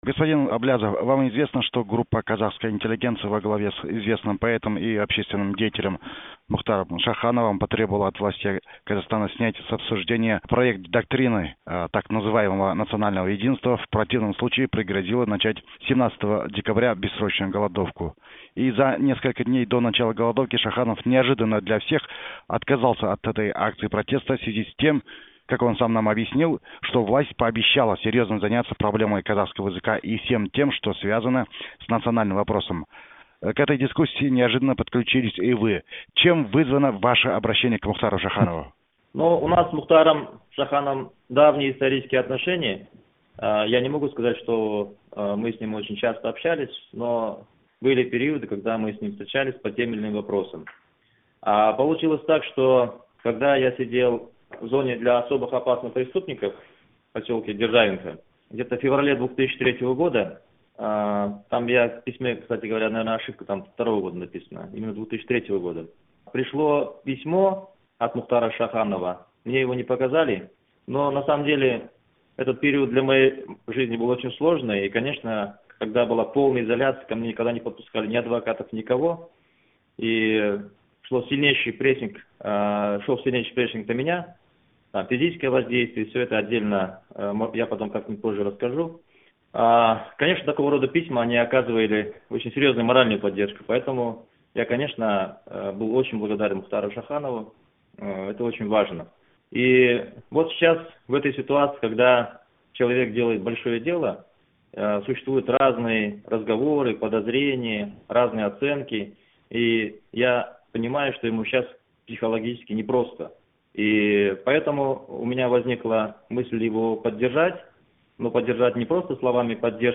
Интервью Мухтара Аблязова.MP3